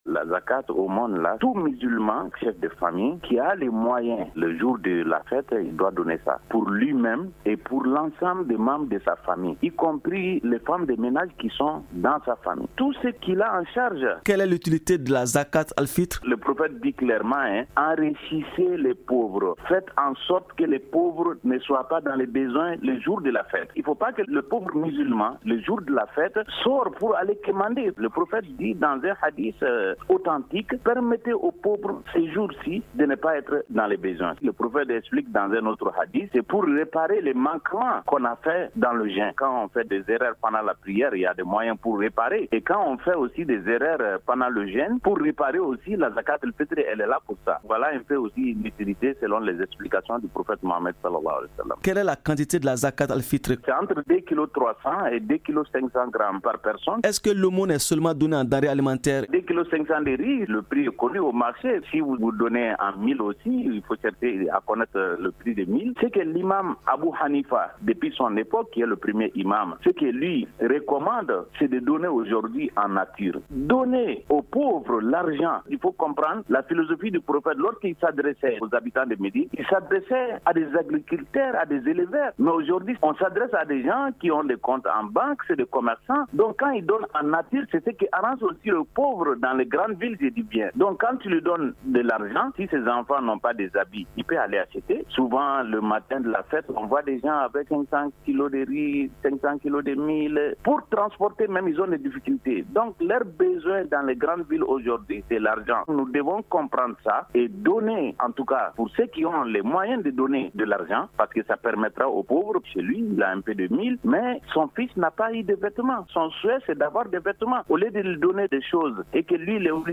est joint au téléphone